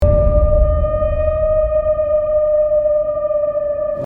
Scary Sound Effect Download: Instant Soundboard Button